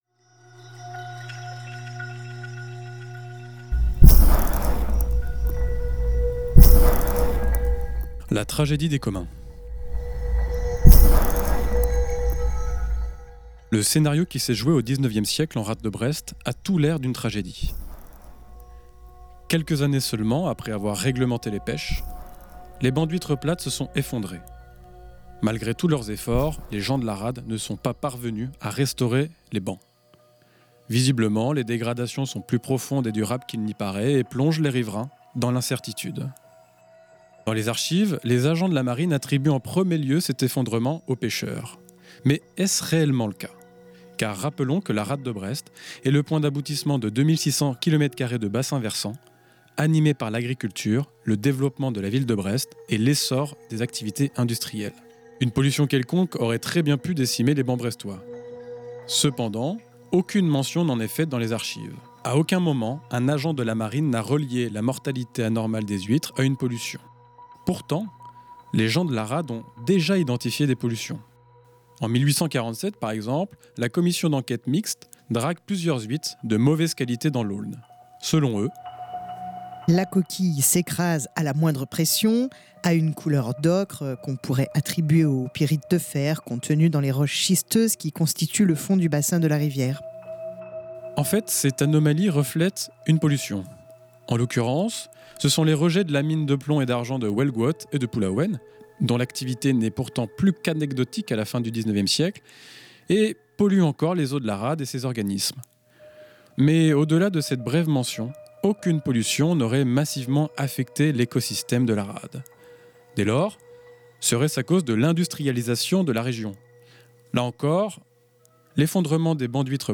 Voici donc le format radio de ce travail, une série de chroniques de quelques minutes à écouter une fois par semaine, le mercredi en début de Lem.